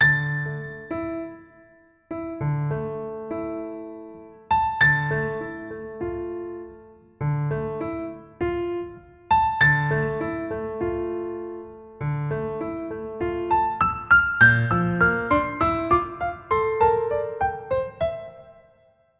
原声钢琴10100bpm
描述：A小调HipHop/Rap钢琴循环播放...